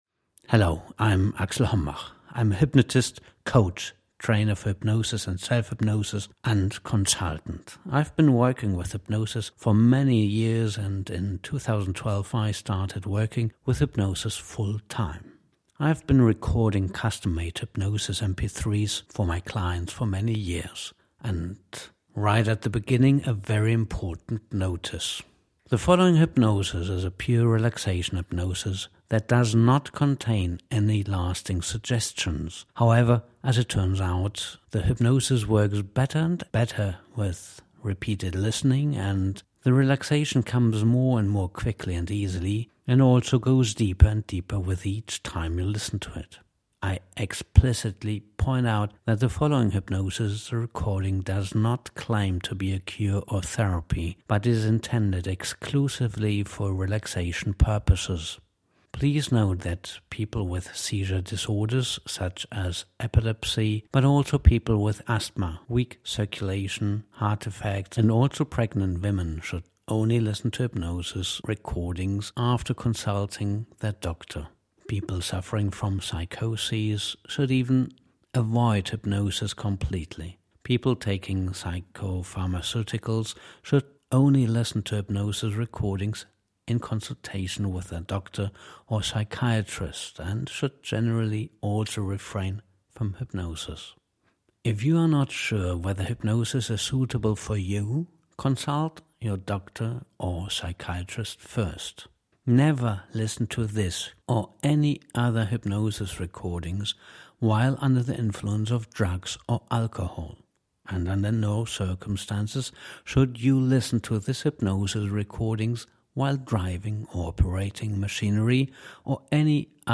noj-relaxation-hypnosis-01-free